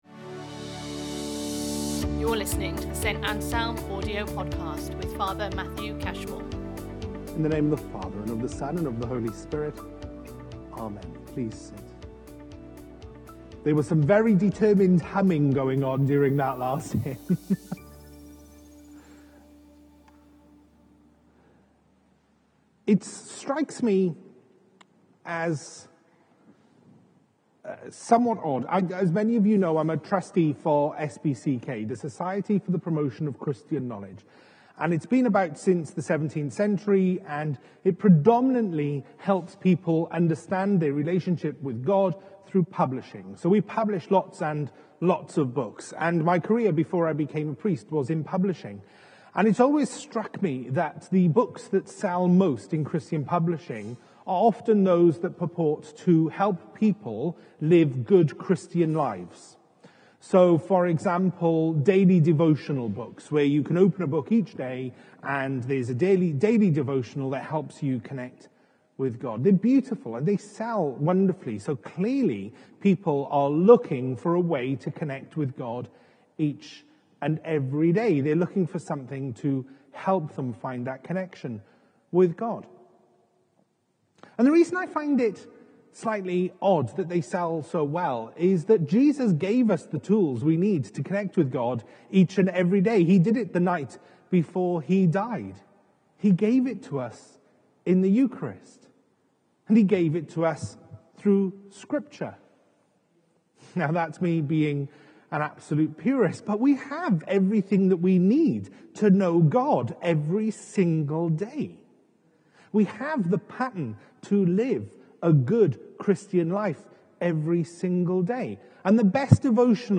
The Eucharist Series Sunday Sermons Book Mark Watch Listen Read Save Mark 14:12-16,22-26 It’s powerful.